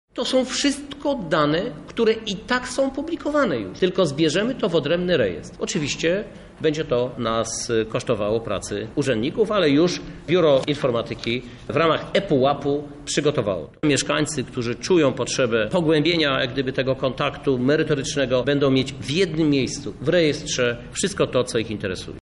To posłuży uszczegółowieniu informacji na co są wydawane pieniądze – mówi Krzysztof Żuk, prezydent Lublina